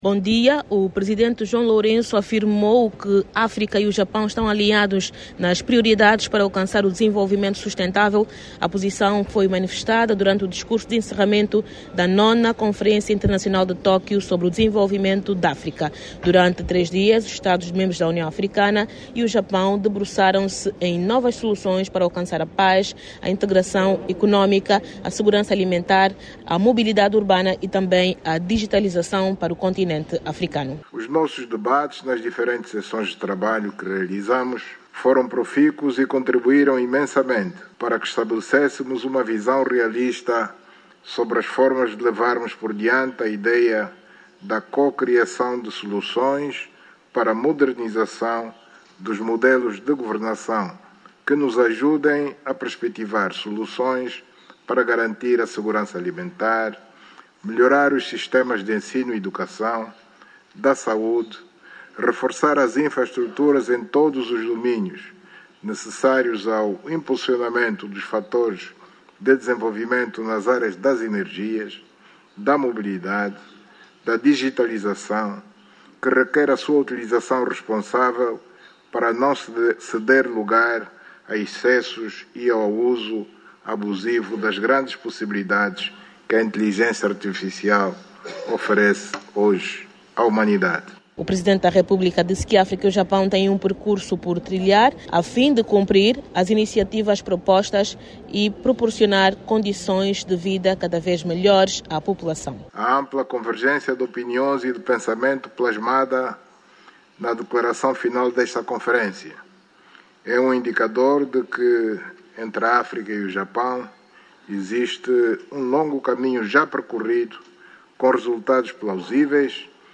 a partir de Yokohama.